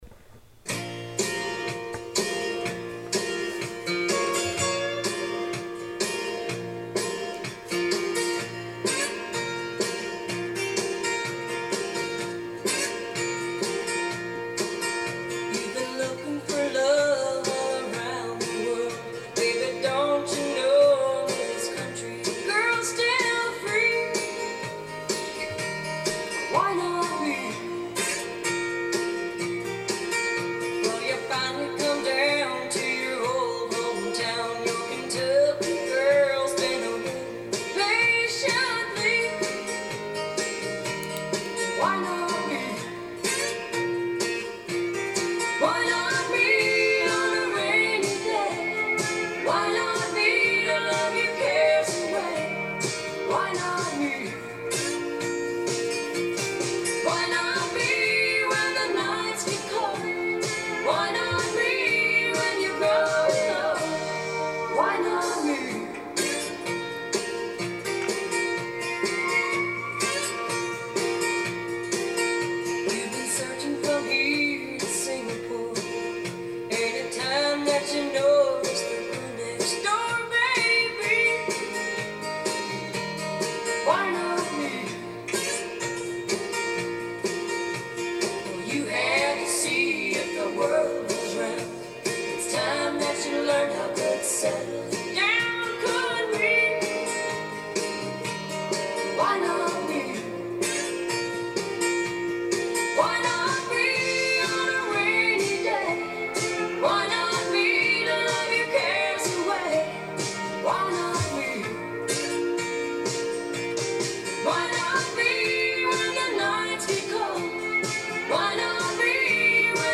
Here are some Traditional Country Music tunes